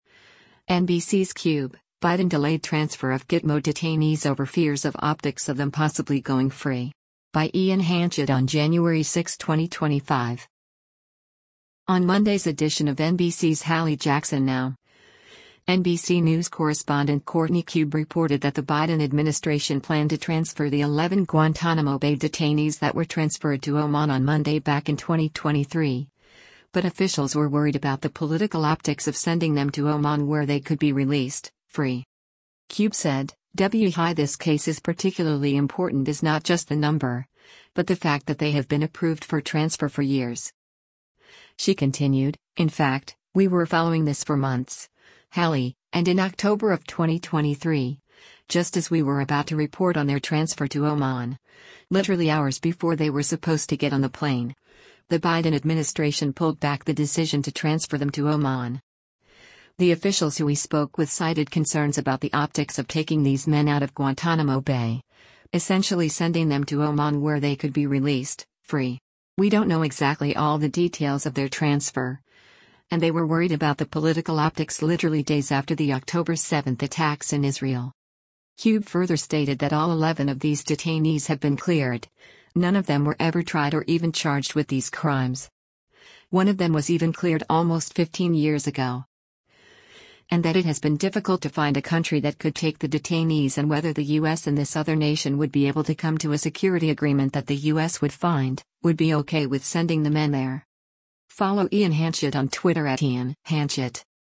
On Monday’s edition of NBC’s “Hallie Jackson Now,” NBC News Correspondent Courtney Kube reported that the Biden administration planned to transfer the eleven Guantanamo Bay detainees that were transferred to Oman on Monday back in 2023, but officials were worried about “the political optics” of “sending them to Oman where they could be released, free.”